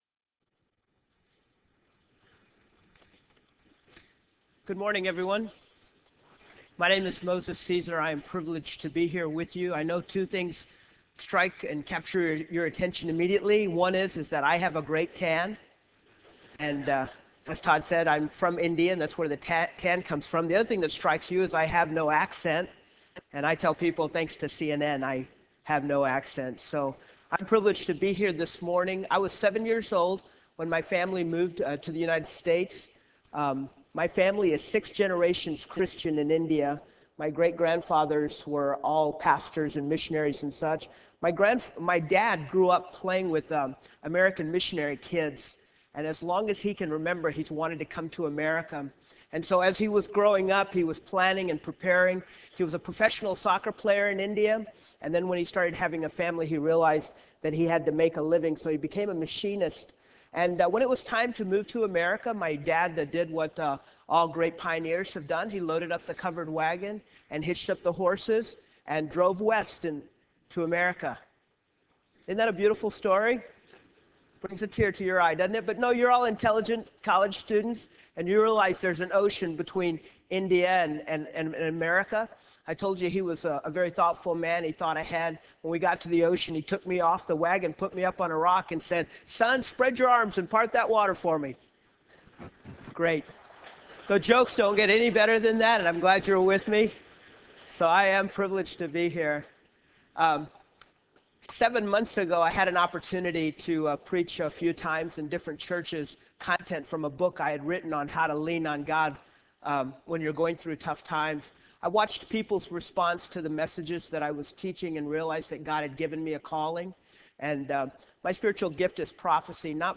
Address: "Looking for Direction"